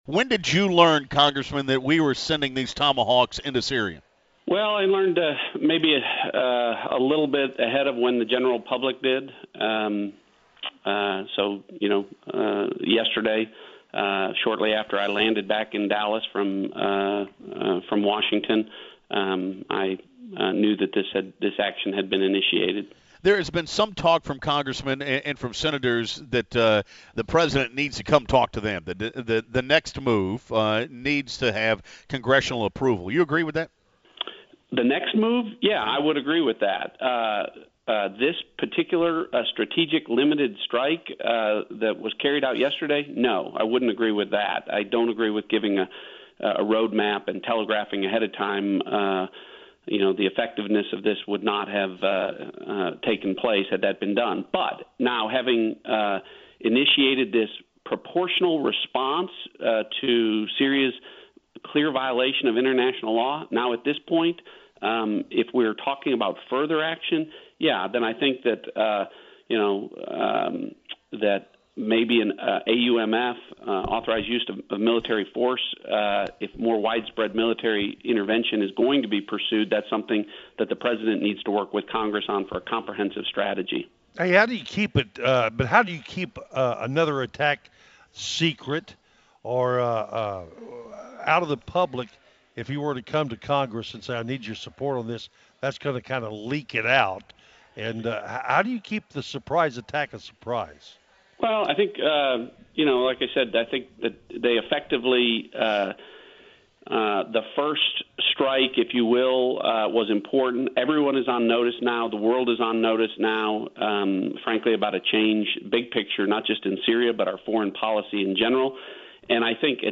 Congressman John Ratcliffe joined the WBAP Morning News and discussed when he learned of the attack, whether or not the President needs Congressional approval to attack again, and the political implications of the attack between Republicans and Democrats.